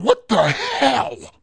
blue-surprised4.mp3